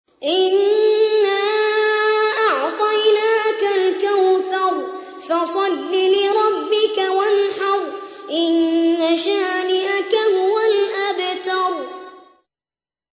قارئ معتمد رواية ورش عن نافع
أحد أشهر قراء القرآن الكريم في العالم الإسلامي، يتميز بجمال صوته وقوة نفسه وإتقانه للمقامات الموسيقية في التلاوة.